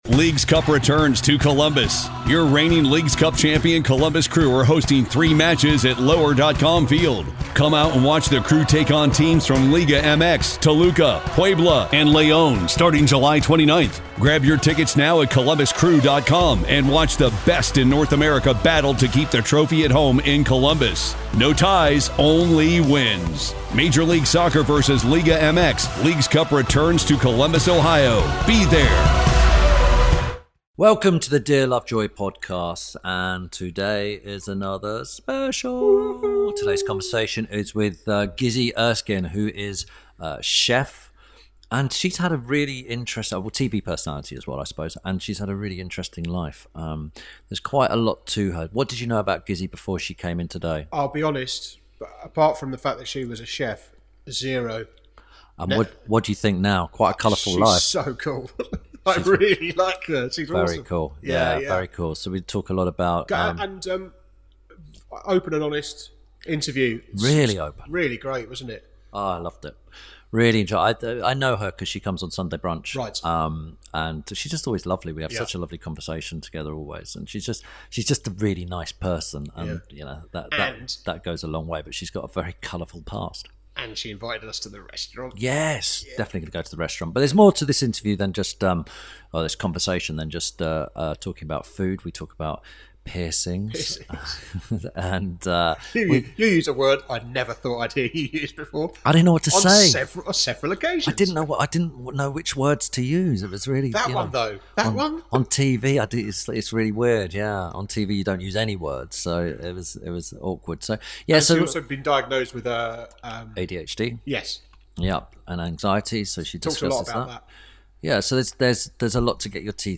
Ep. 86 – GIZZI ERSKINE - A Conversation With… – INTERVIEW SPECIAL
This week Tim Lovejoy talks to chef and restaurateur Gizzi Erskine. Tim and Gizzi discuss her unusual route into the restaurant world, including being a DJ, and training to be a professional body piercer.